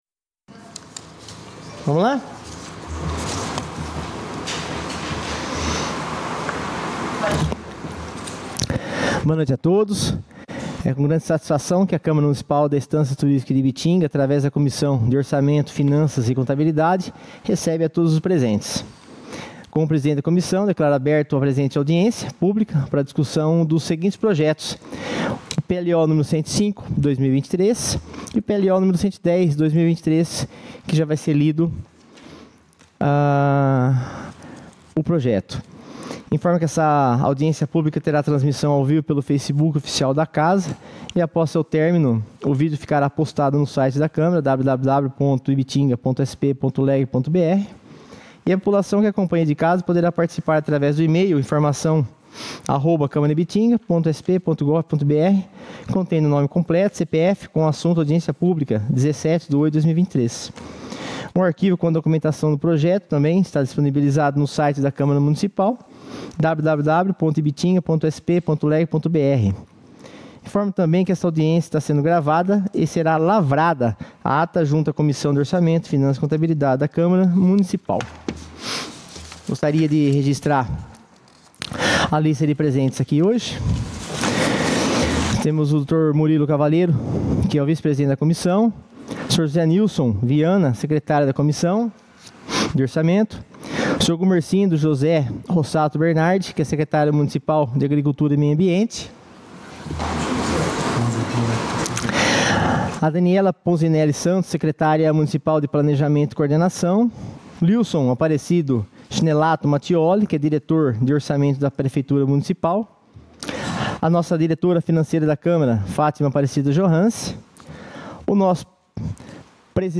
audiencia comissão orçamento